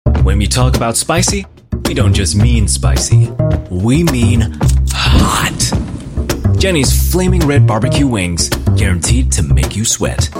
EN Asian
male